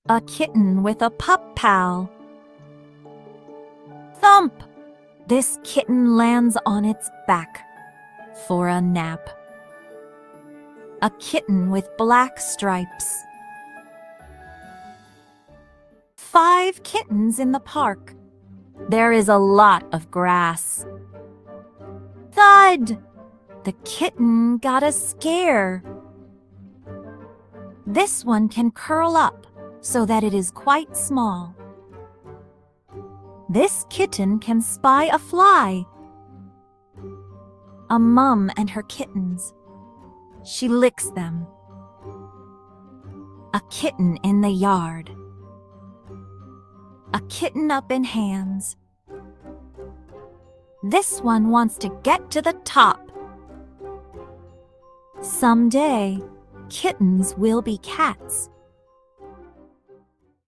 Words containing the digraph 'th', producing either the unvoiced /θ/ or voiced /ð/ sound.